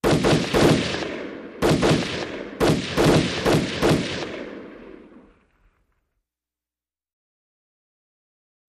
Automatic Weapon 3, Single & Multiple Bursts, Echoey.